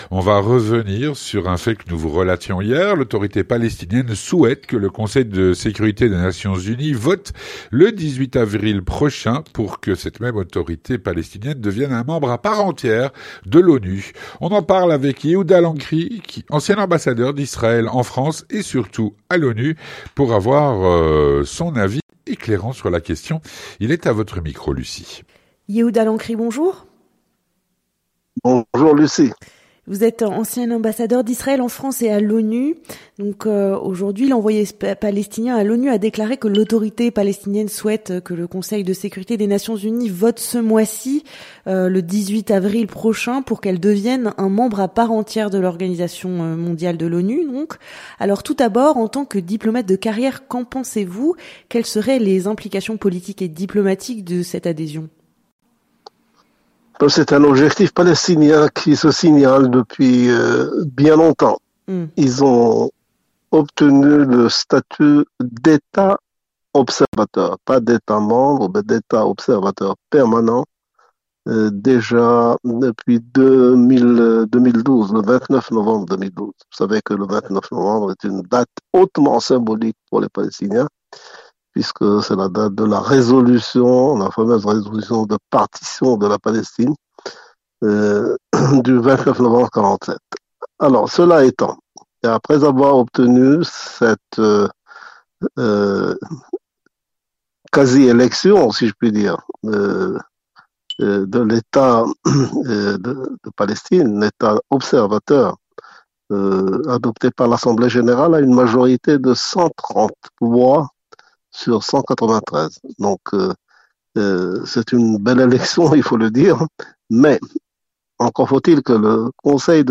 L'entretien du 18H - L'Autorité palestinienne souhaite que le Conseil de sécurité des Nations unies vote le 18 avril prochain pour qu'elle devienne un membre à part entière de l'ONU.
Avec Yehuda Lancry, ancien ambassadeur d’Israël en France et à l’ONU.